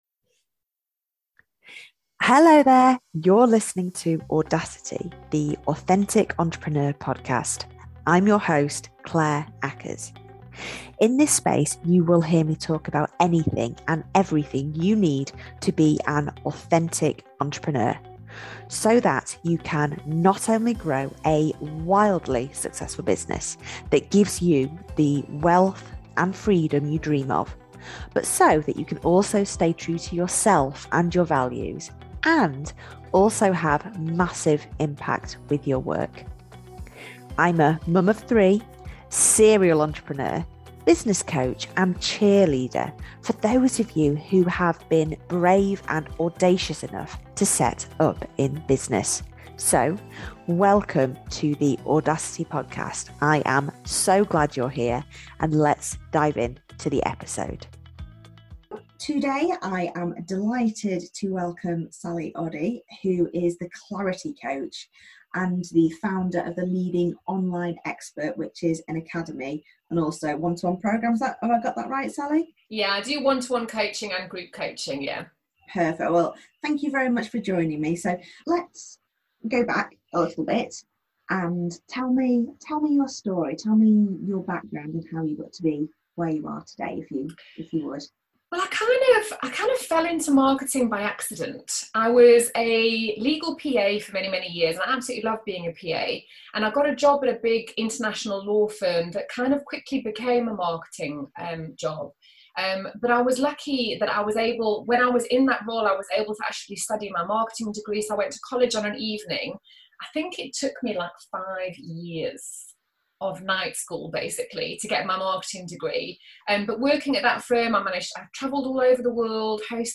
Online Marketing, Mindset & Magic - Interview